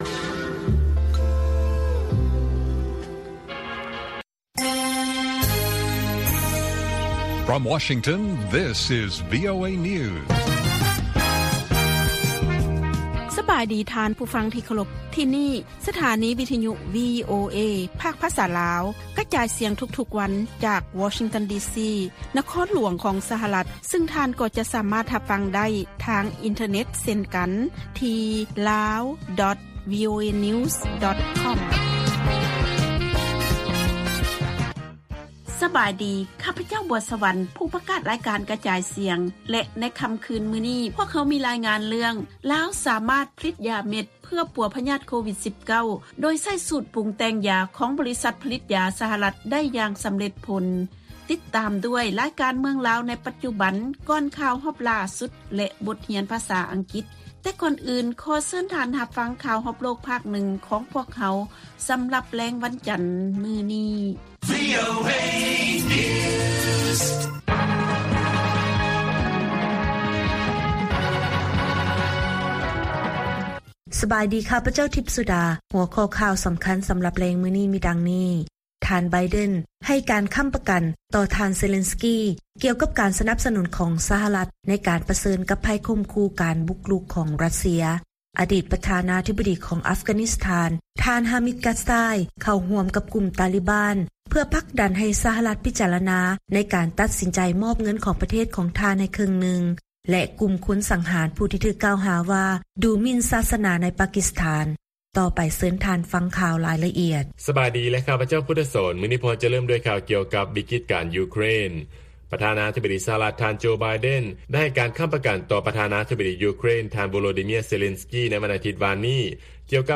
ລາຍການກະຈາຍສຽງຂອງວີໂອເອ ລາວ: ທ່ານ ໄບເດັນ ໃຫ້ການຄໍ້າປະກັນ ຕໍ່ ທ່ານ ເຊເລັນກີ ກ່ຽວກັບ ການສະໜັບສະໜຸນຂອງສະຫະລັດ ໃນການປະເຊີນກັບໄພຂົ່ມຂູ່ຂອງຣັດເຊຍ